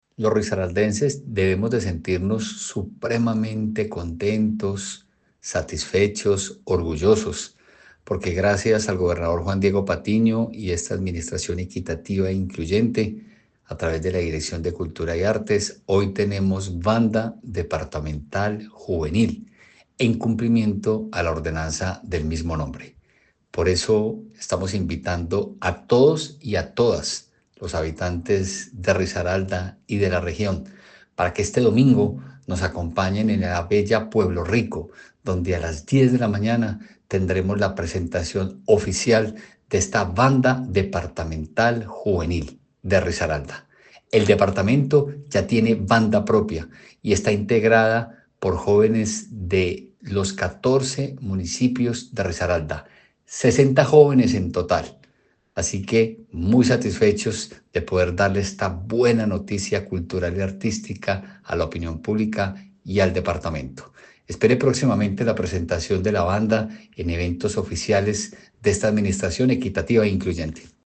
ANDRES-GARCIA-MARTINEZ-DIRECTOR-DE-CULTURA-DE-RISARALDA.mp3